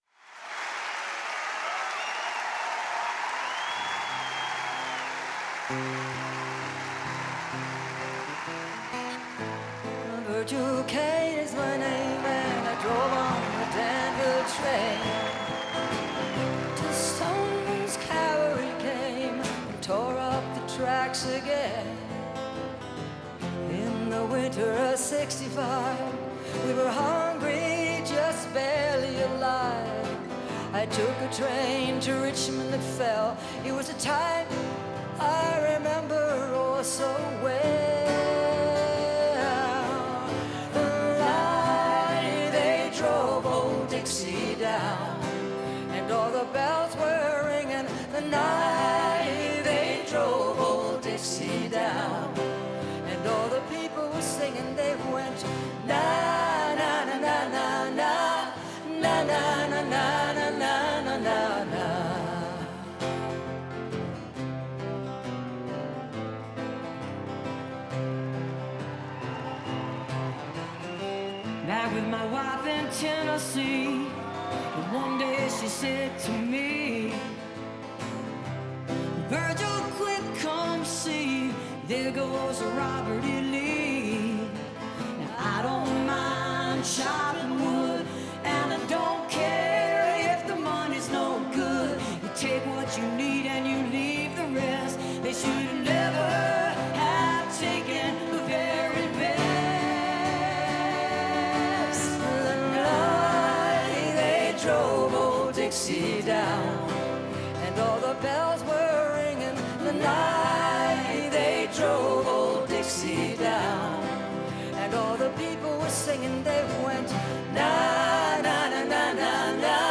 (recorded from youtube)